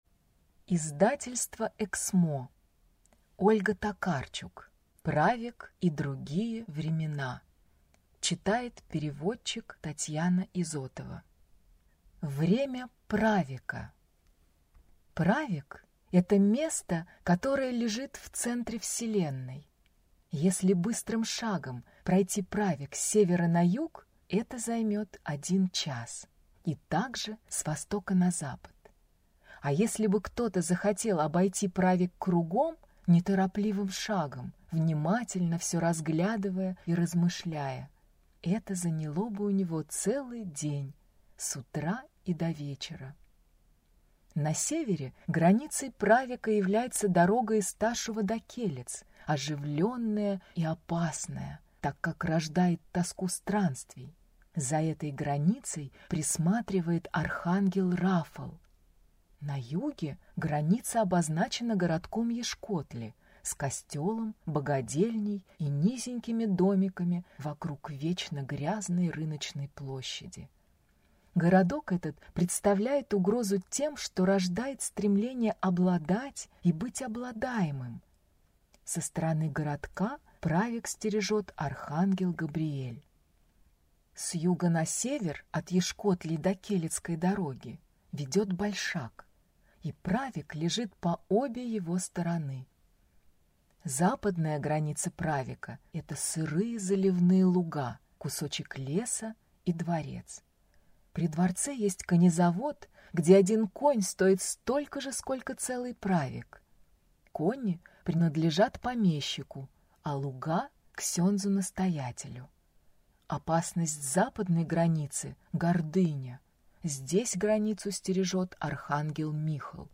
Аудиокнига Правек и другие времена | Библиотека аудиокниг